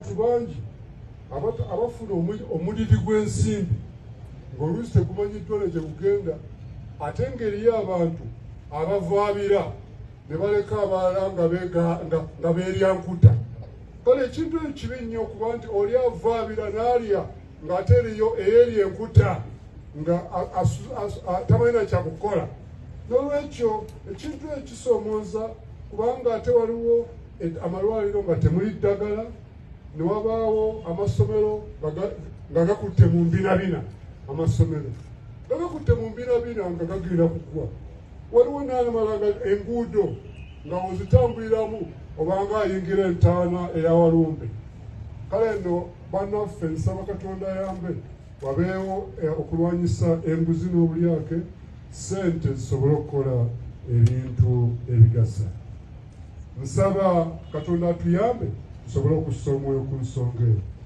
Speaking during his Easter message at the Bishop’s House Namirembe Hill, Kampala on Wednesday, Archbishop Kaziimba criticized government officials who embezzle public funds meant for essential services. He said the money intended to develop schools, improve infrastructure, and procure medicines for hospitals is being diverted by a few corrupt individuals.